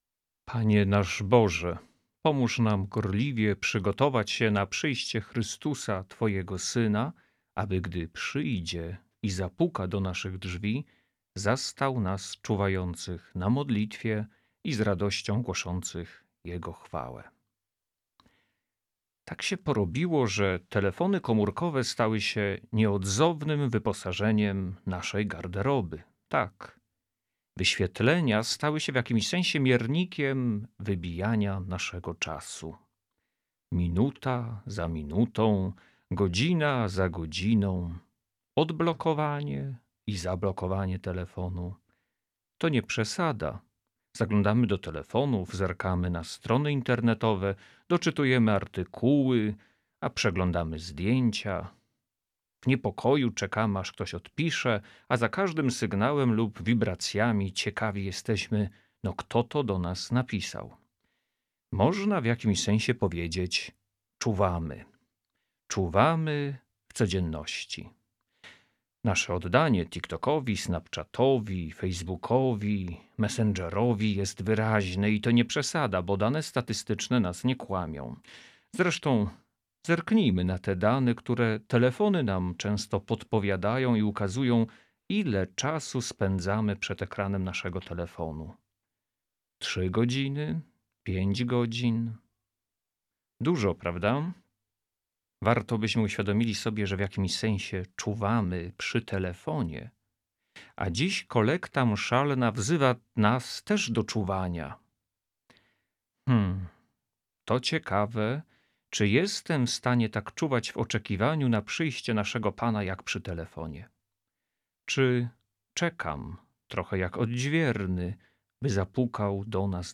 Rozważania adwentowe w Radiu Rodzina
Rozważania dla Radia Rodzina przygotowali klerycy diakoni Metropolitalnego Wyższego Seminarium Duchownego we Wrocławiu.
Emisja codziennie po porannej Mszy Św. i po Apelu Jasnogórskim (godz. 6:50 i 21:20).